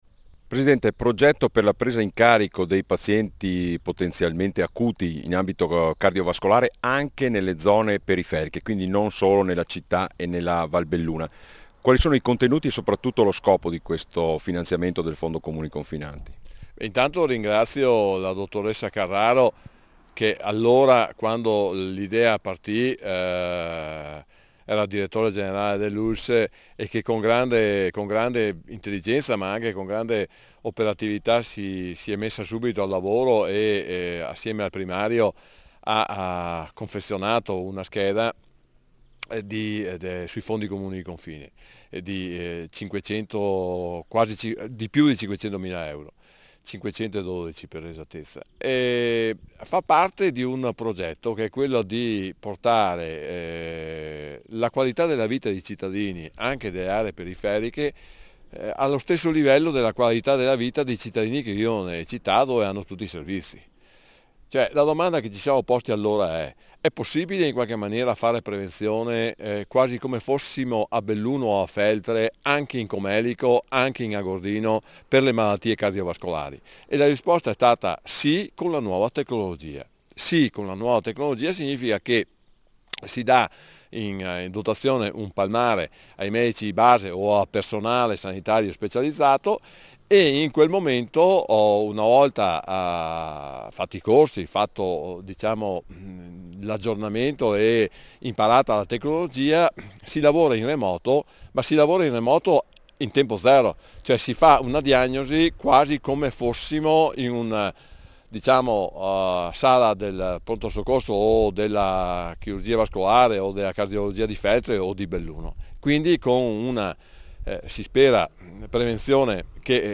IL PRESIDENTE DEL FONDO COMUNI CONFINANTI, DARIO BOND